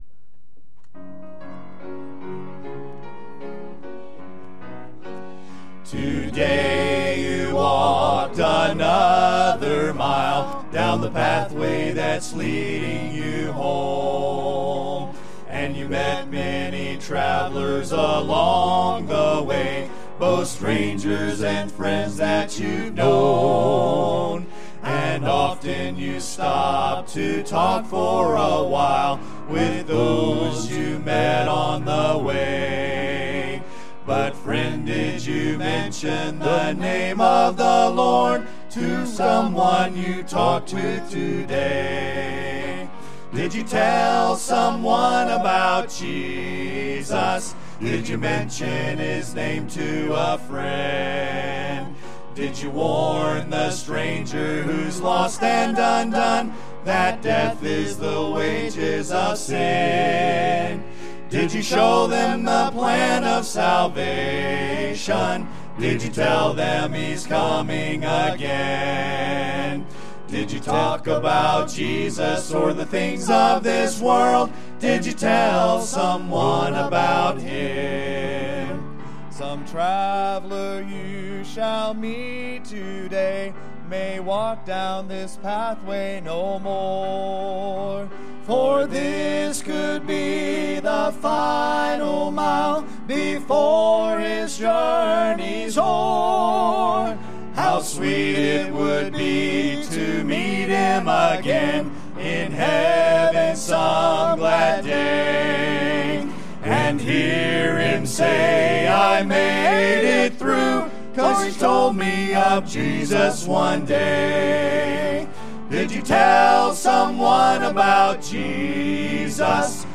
Sunday Morning – 11/03/2024